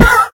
sounds / mob / horse / hit1.ogg